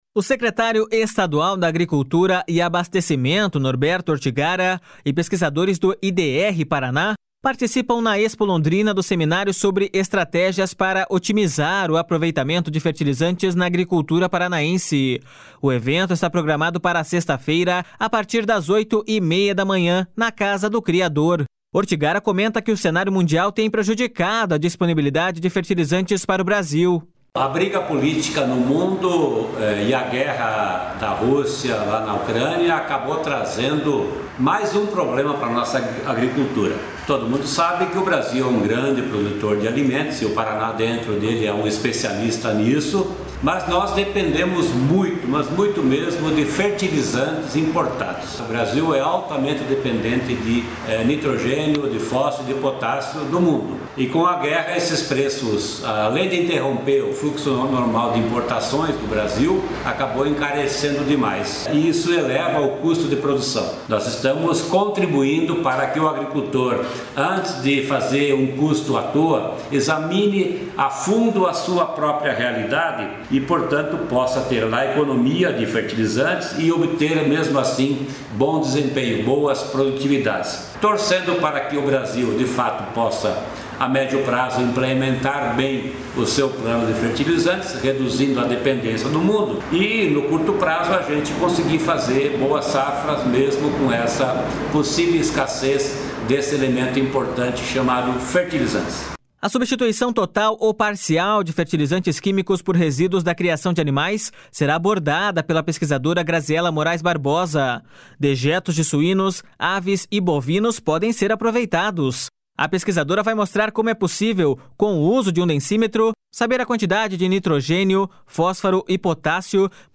Ortigara comenta que o cenário mundial tem prejudicado a disponibilidade de fertilizantes para o Brasil.// SONORA NORBERTO ORTIGARA.//